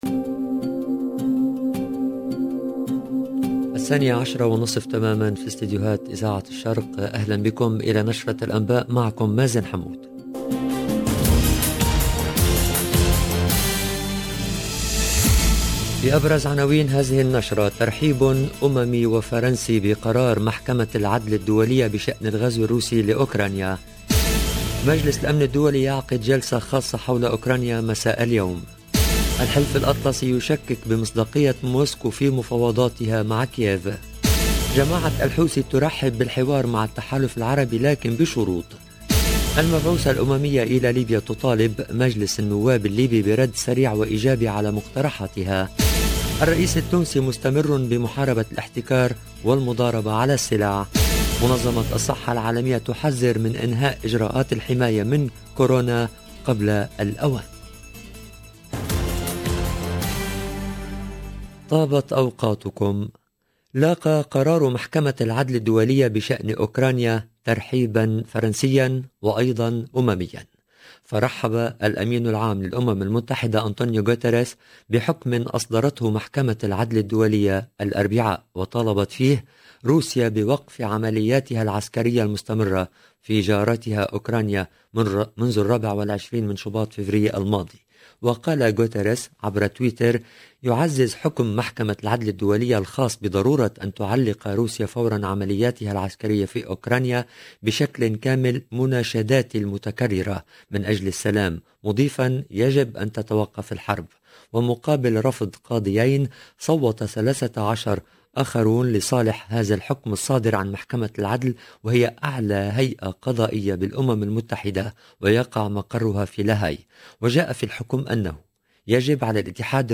LE JOURNAL DE MIDI 30 EN LANGUE ARABE DU 17/03/22